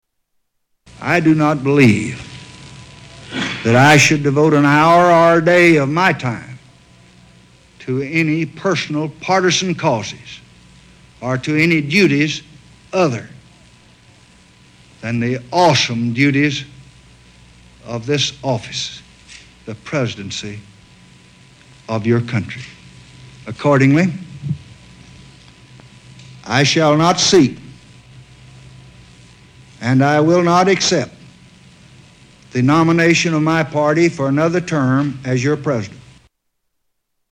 Tags: Historical Lyndon Baines Johnson Lyndon Baines Johnson clips LBJ Renunciation speech